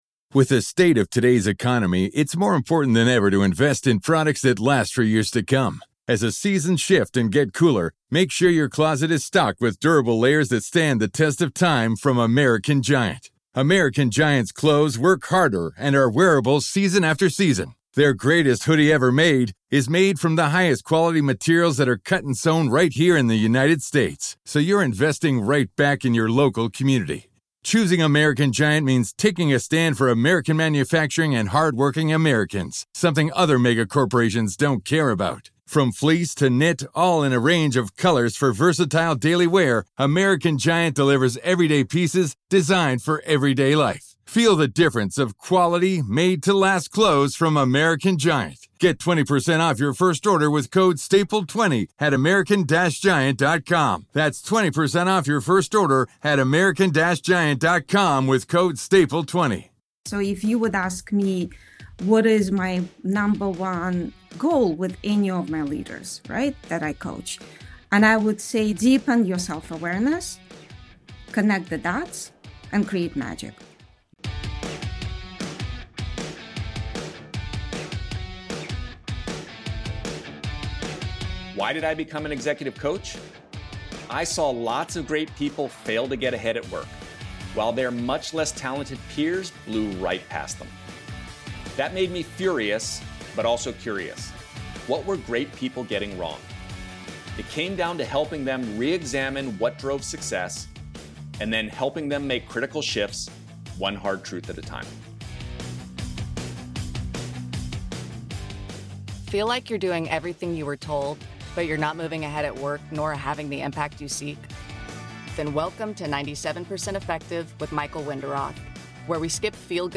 Season #1 focuses on Power and Influence, two widely acknowledged (but poorly understood) forces that can help you rise, lead more effectively and get big things done. Each week we have candid conversations with an academic, coach, or executive, helping you gain new insights to better navigate your work and career.